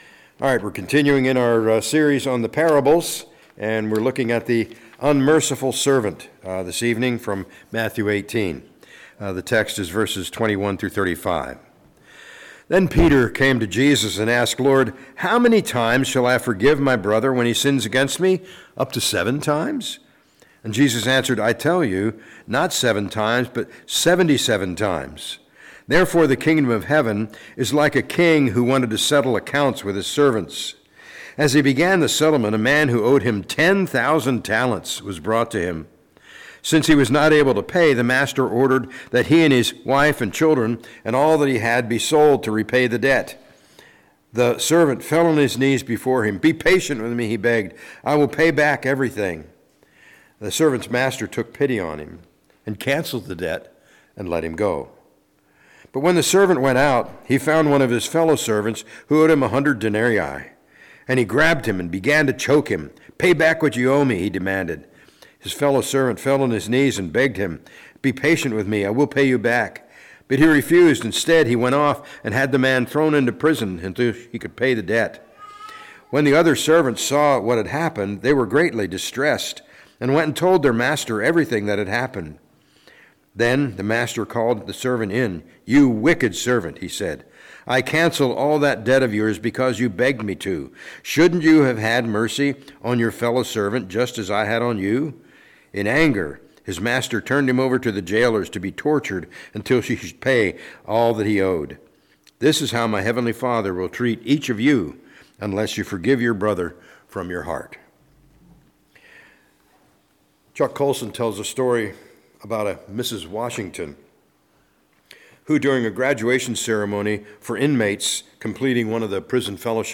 A message from the series "Parables of Jesus."